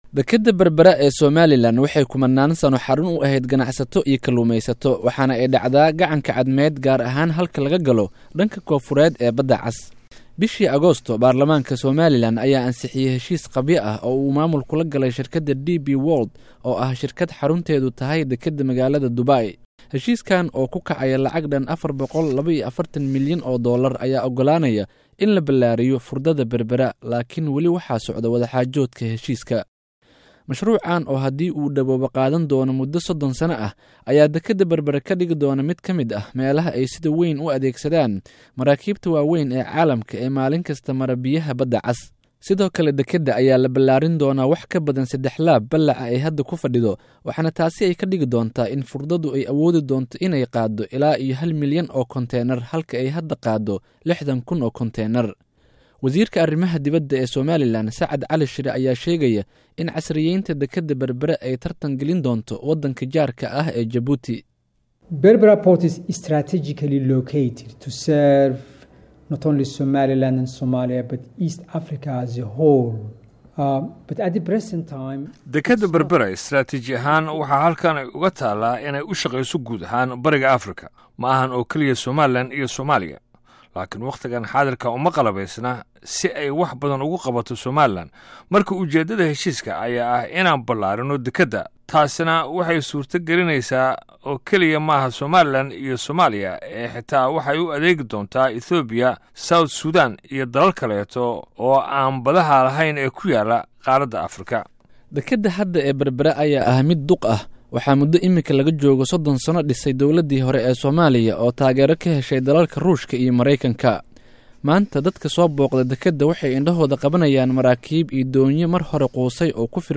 Warbixin: Dekedda Berbera